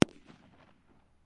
爆炸 " 004 烟花
Tag: 响亮 繁荣 爆炸 烟花 爆竹 爆炸